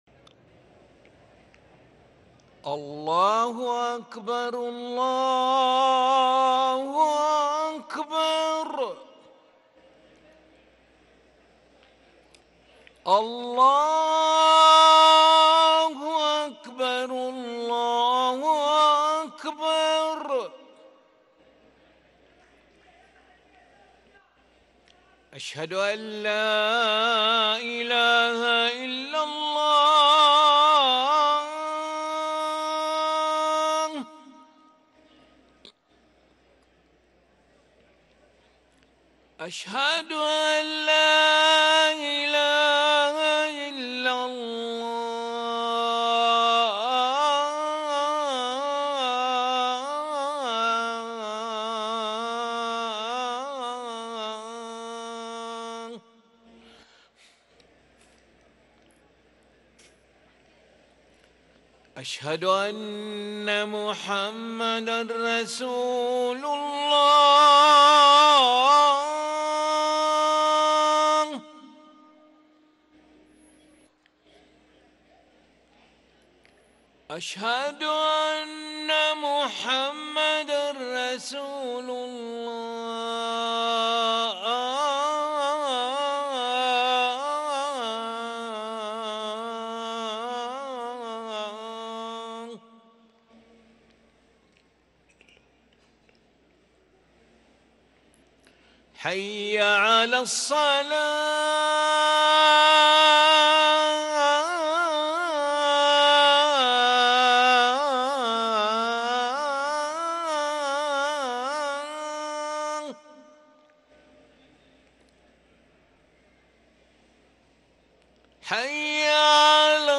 أذان العشاء للمؤذن علي ملا الأحد 20 ربيع الأول 1444هـ > ١٤٤٤ 🕋 > ركن الأذان 🕋 > المزيد - تلاوات الحرمين